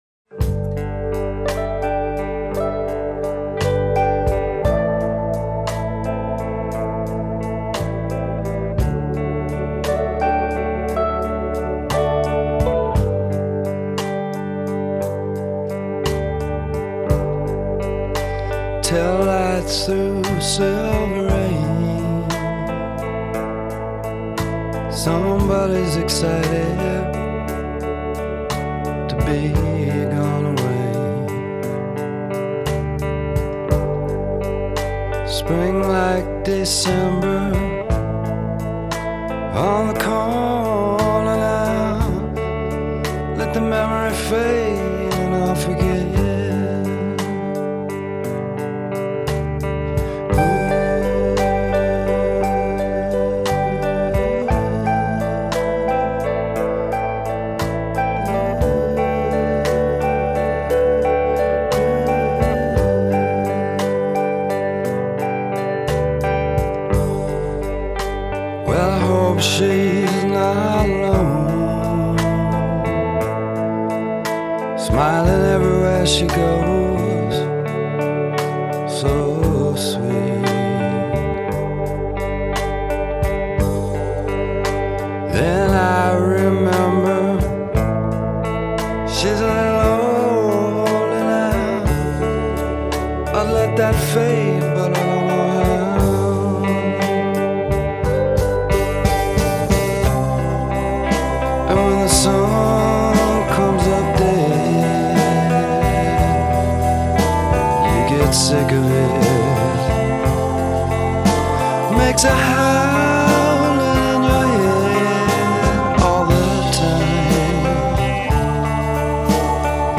迷人的白色噪音底子隐没成布帘点缀的星光
有着清淡着色的回转，笼罩起薄纱般透明微亮的清晨，一点一点逐渐消失在呼吸的尾梢